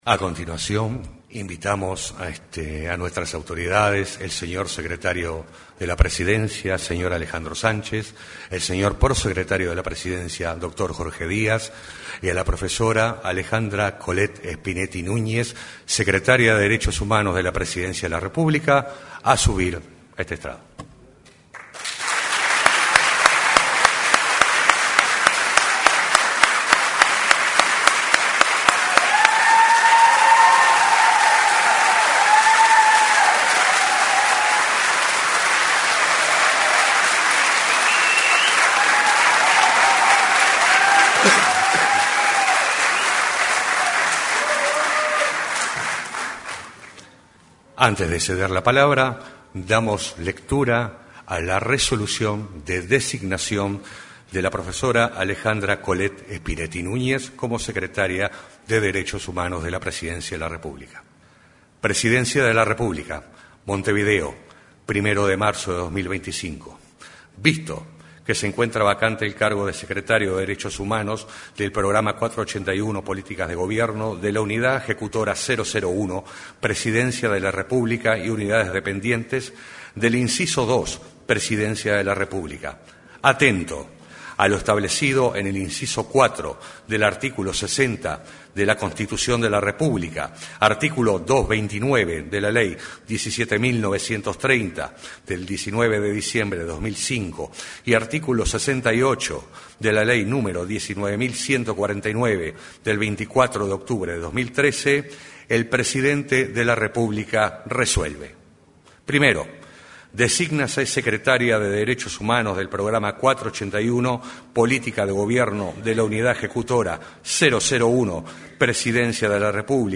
En la oportunidad, se expresaron: la novel secretaria, Collette Spinetti; el secretario de Presidencia de la República, Alejandro Sánchez, y el prosecretario de Presidencia de la República, Jorge Díaz.